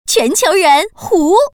Index of /hunan_feature2/update/1271/res/sfx/common_woman/